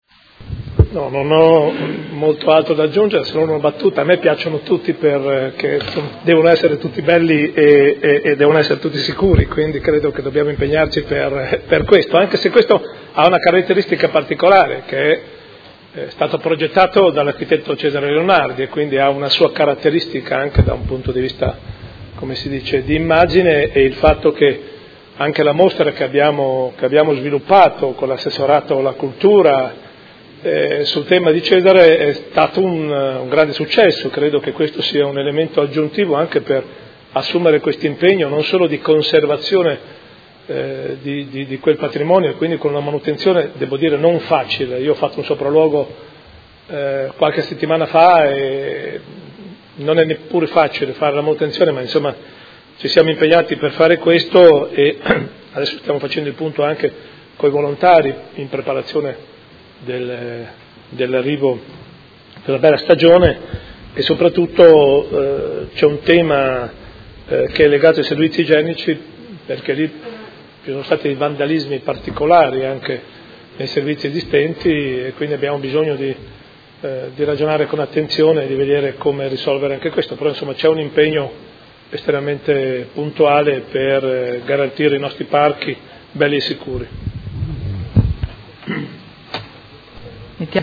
Sindaco — Sito Audio Consiglio Comunale